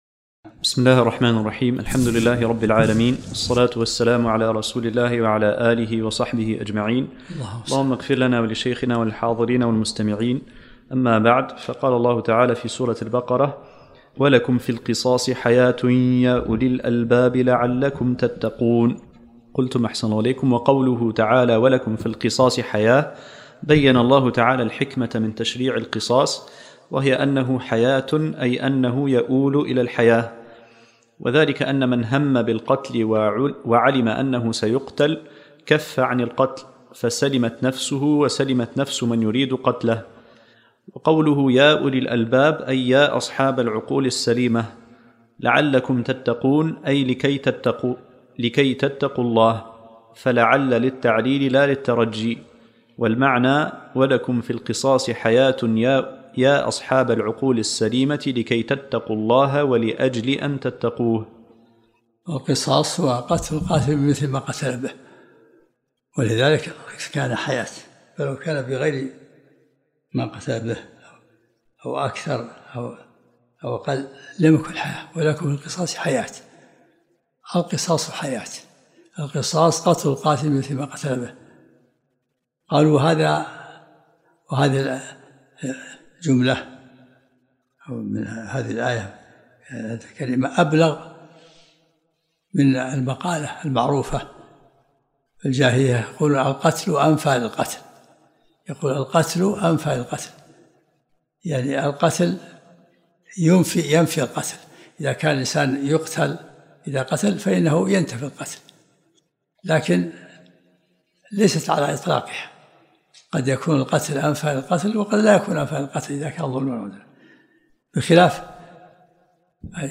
الدرس الثالث عشرمن سورة البقرة